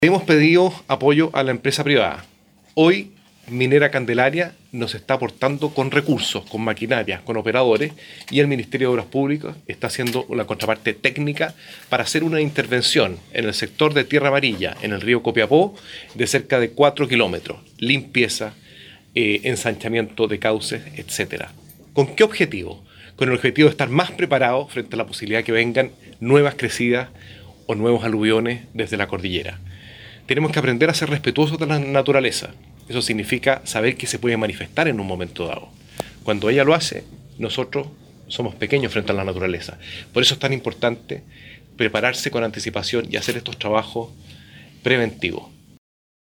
La ceremonia de firma fue encabezada por el subsecretario de Obras Públicas, Lucas Palacios, el cual se mostró muy satisfecho con este acuerdo, señalando que habían recibido el mandato del Presidente Piñera de poder fortalecer la infraestructura de cauce y ribera del Río Copiapó, para evitar crecidas como las registradas en 2015 y 2017: